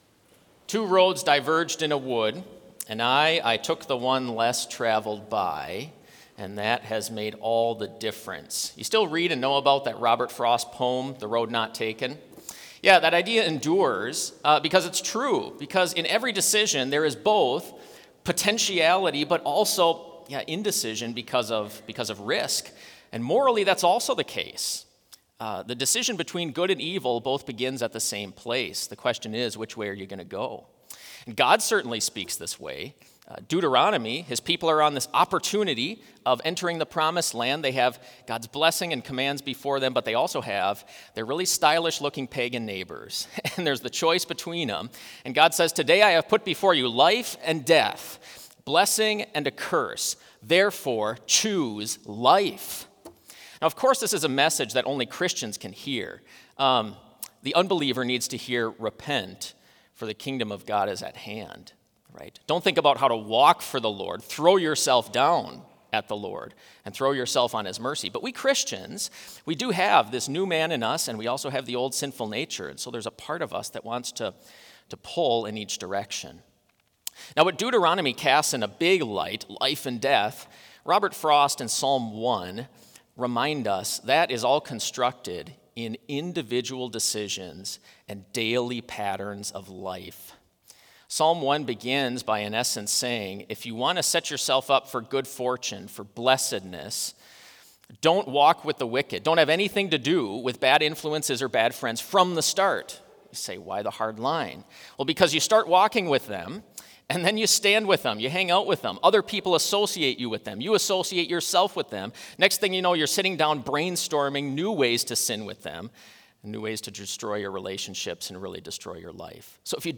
Complete Service
spoken in unison, and "Glory be ..."
This Chapel Service was held in Trinity Chapel at Bethany Lutheran College on Tuesday, November 18, 2025, at 10 a.m. Page and hymn numbers are from the Evangelical Lutheran Hymnary.